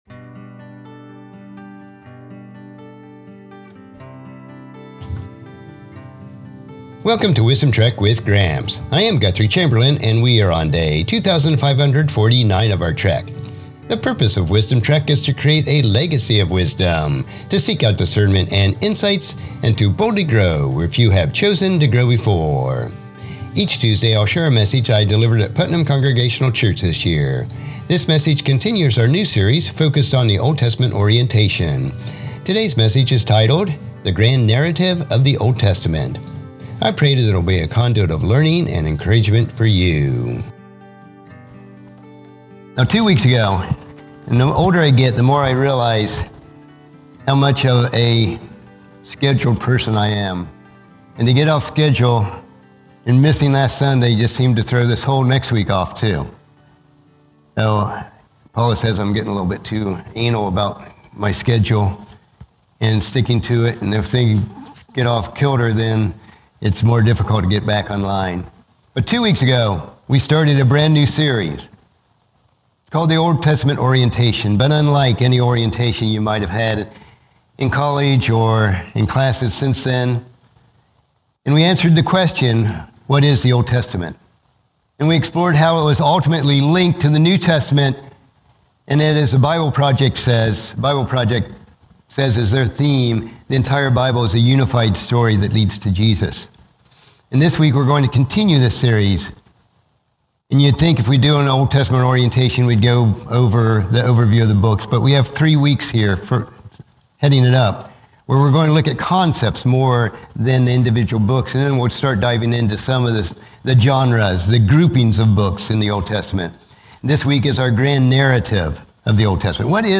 Sermon Series: Old Testament Orientation Message 2: The Grand Narrative of the Old Testament